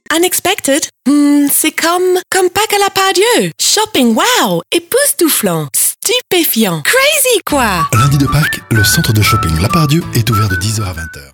Sprechprobe: Werbung (Muttersprache):
Home studio, prompt delivery.